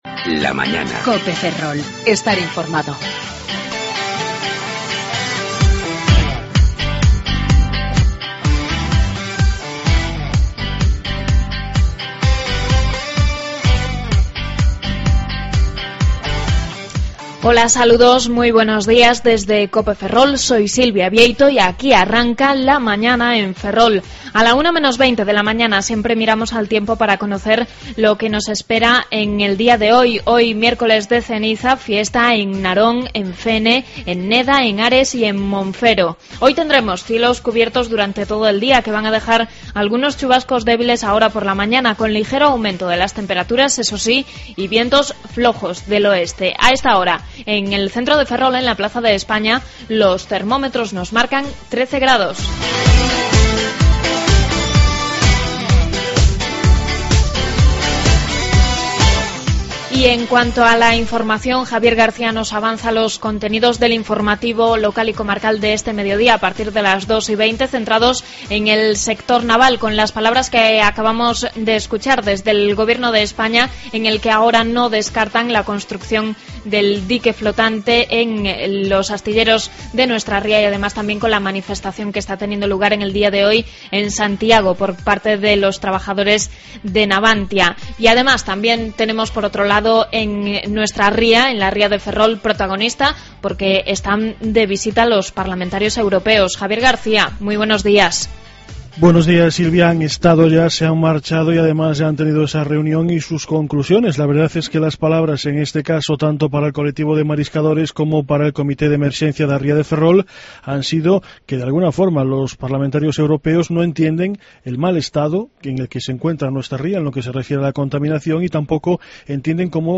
Redacción digital Madrid - Publicado el 13 feb 2013, 16:59 - Actualizado 14 mar 2023, 15:41 1 min lectura Descargar Facebook Twitter Whatsapp Telegram Enviar por email Copiar enlace Avances informativos y contenidos de Ferrol, Eume y Ortegal.